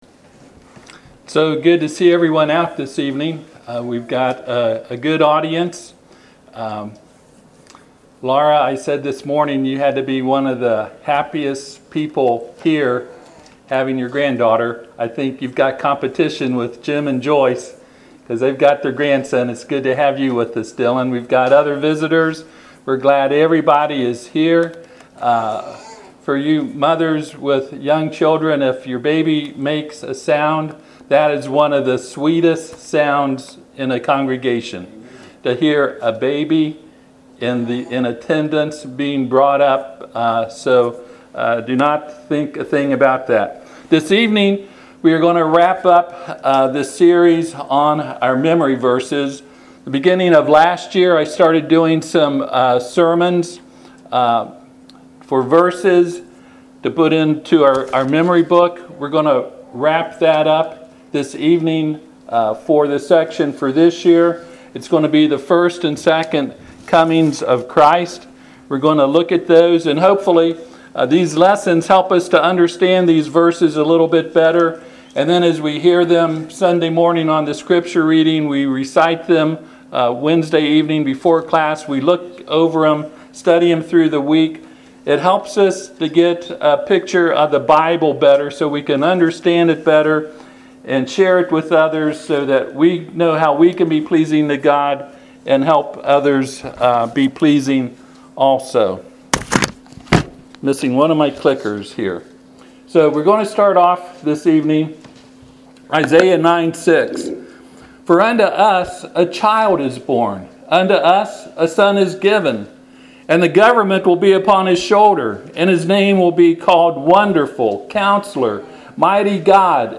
Acts 1:11 Service Type: Sunday PM Acts 1:11 who also said